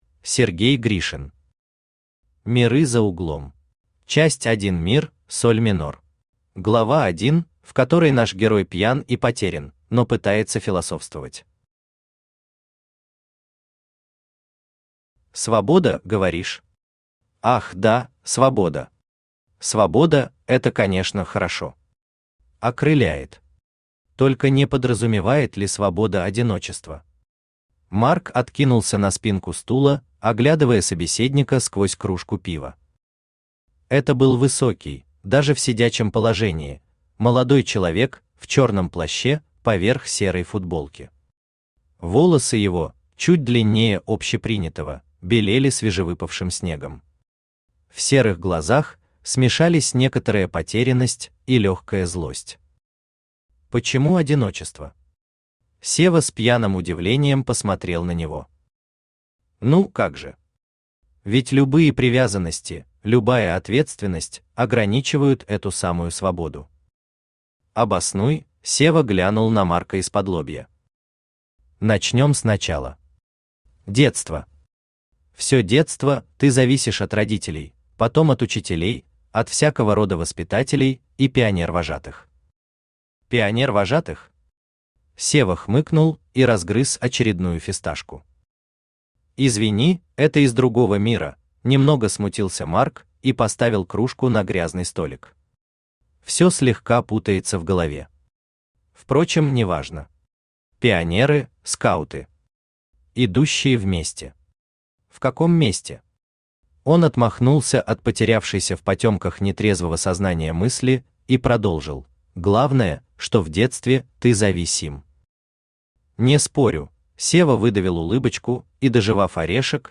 Aудиокнига Миры за углом Автор Сергей Гришин Читает аудиокнигу Авточтец ЛитРес.